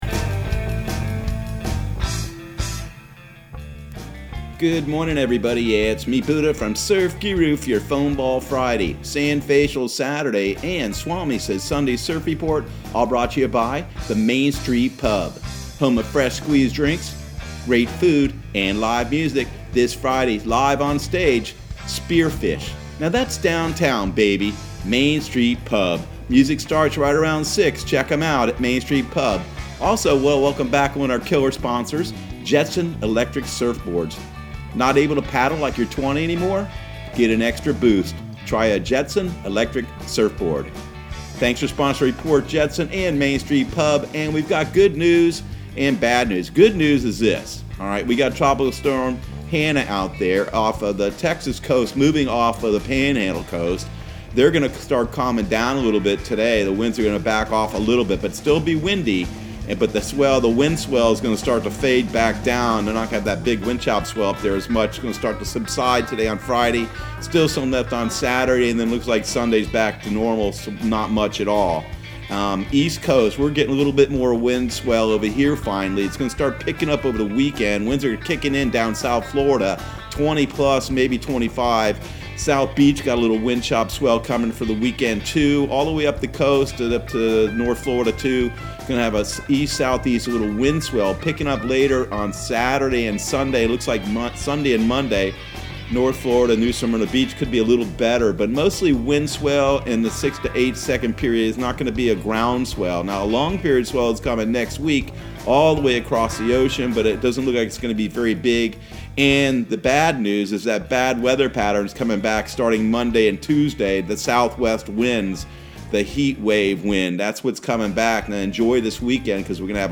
Surf Guru Surf Report and Forecast 07/24/2020 Audio surf report and surf forecast on July 24 for Central Florida and the Southeast.